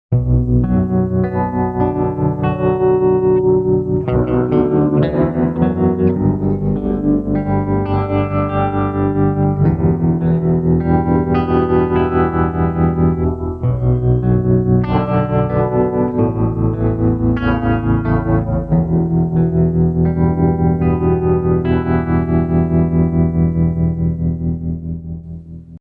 • TREM – three modes: a classic, periodic volume envelope with various shapes applied to both the wet and dry mix of a hall reverb
• POLYPHONIC – two modes: two configurable bi-directional pitch shifts that add dimensionality to the reverb tail
Dual Reverb in Parallel Poly & Trem
Oceans-12Dual-Stereo-Reverb-Dual-in-Parallel-Poly-Trem.mp3